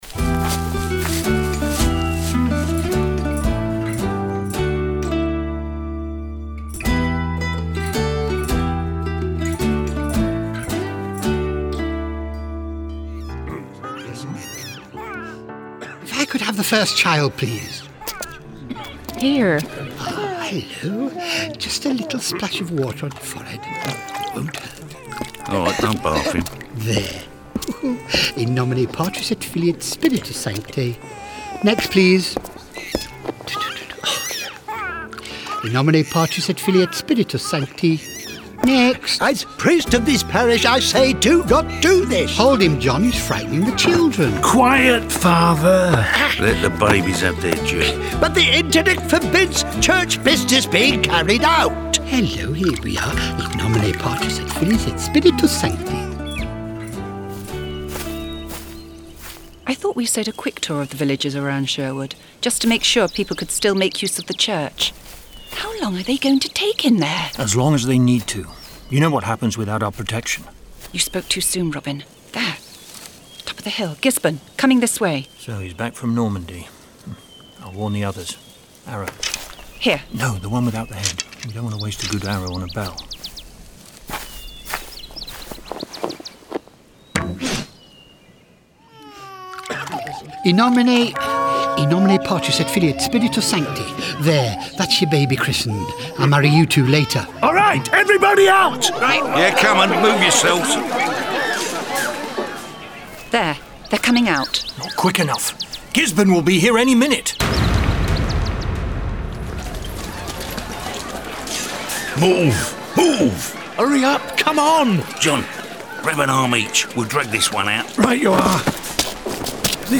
Słuchowisko spełniło swoją rolę doskonale i wystarczyło odrzucić na bok kilka kwestii, które być może na początku nie tyle rażą, co doskwierają (w głosie Jasona Connerego słychać najmocniej upływ lat) i dostaniemy pełnoprawną opowieść o drużynie Robina.
Tak jest! Clannad uderzył raz jeszcze w znajome nuty, a później wkroczyłem do lasu i ponownie ich zobaczyłem (NAPRAWDĘ). The Knight of the Apocalypse opowiada o czasie, gdy papież ekskomunikował króla Anglii, a wpływy zaczął zwiększać tajemniczy zakon templariuszy o nazwie Rycerze Apokalipsy.
Spokojny i wyważony ton Nasira jak zwykle (chociaż przecież minęło 30 lat) kłóci się z porywczym głosem Willa.
Odsłuchów miałem kilka i za każdym razem wyłapywałem kolejne smaczki: zaklęcie templariusza recytowane po cichutku w tle, wszystkie odgłosy wsi, później lasu, o wojennej zawierusze nie wspominając.